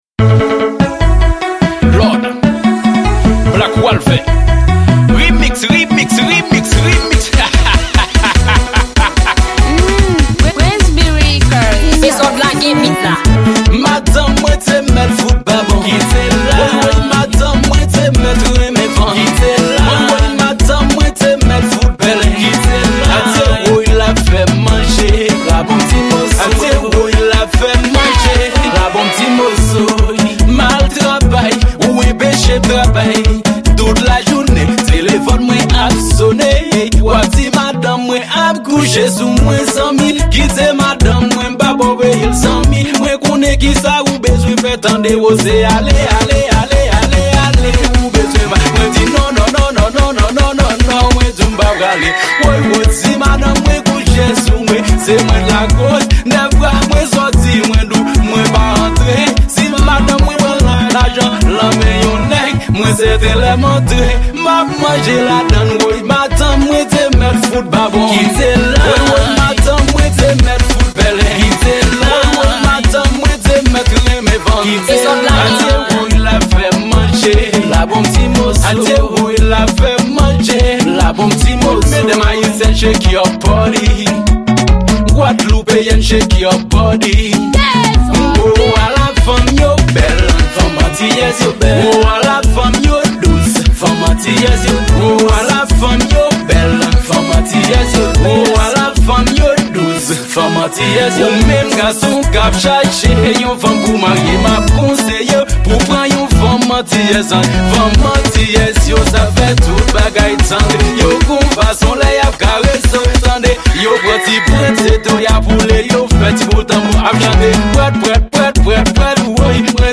Genre: Raboday.